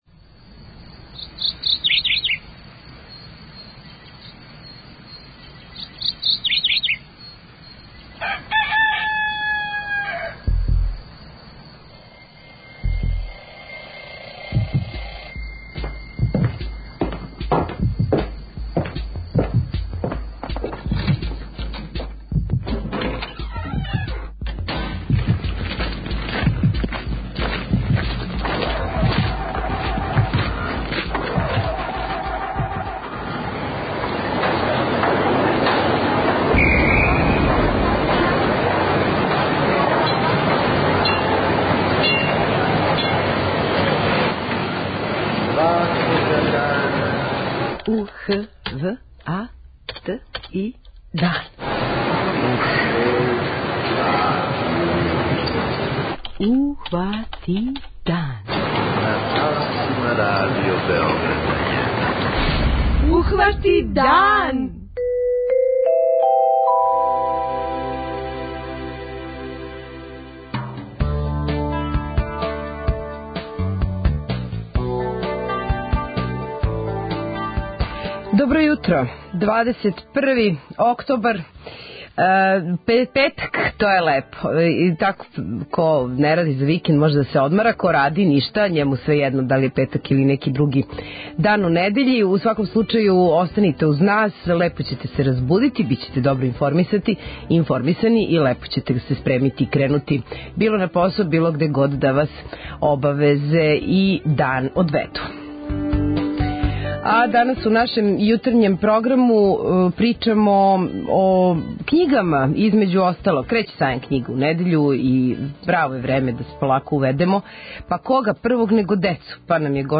И овог петка у квизу јутарњег програма имаћете прилику да освојите вредне награде.
преузми : 21.58 MB Ухвати дан Autor: Група аутора Јутарњи програм Радио Београда 1!